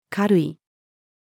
軽い-female.mp3